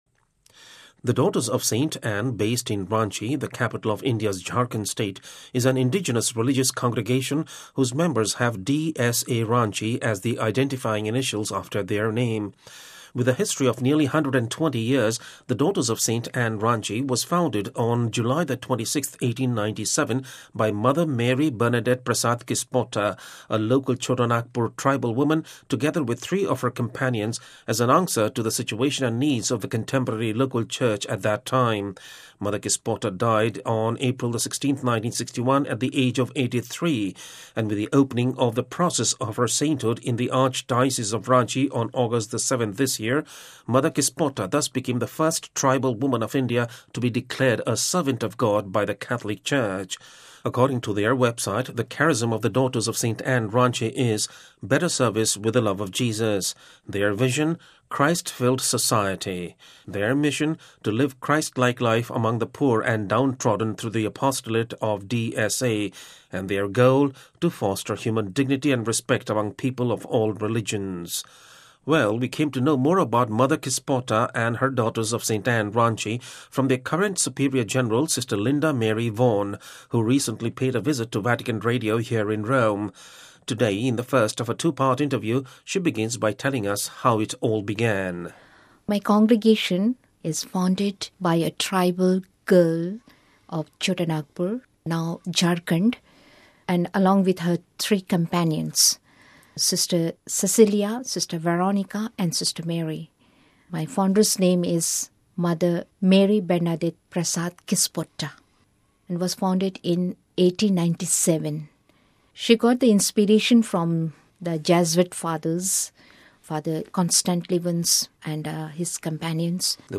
Today, in the first of a 2-part interview, she begins by telling us how it all began.